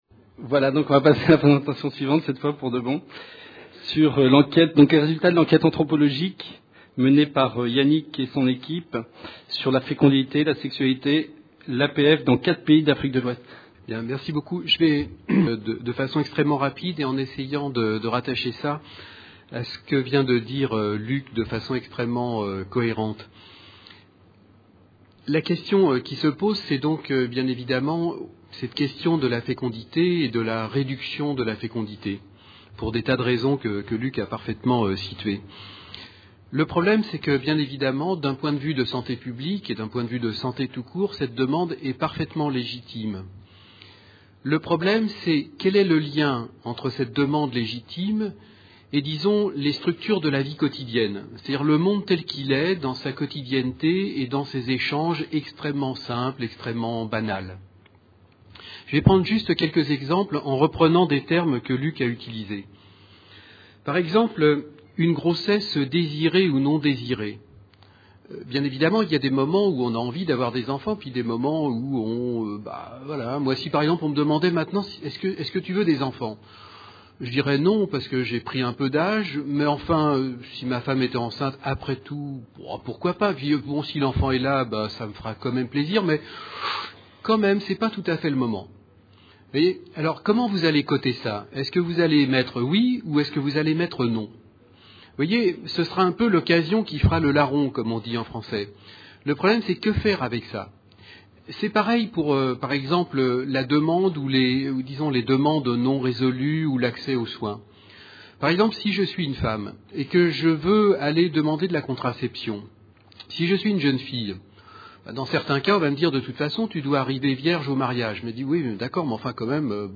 Réduction de la mortalité maternelle - Dakar 2010 : Résultats de l’enquête anthropologique menée sur la fécondité, la sexualité et le planning familial dans 4 pays d’Afrique de l’ouest. Conférence enregistrée dans le cadre du Colloque International Interdisciplinaire : Droit et Santé en Afrique.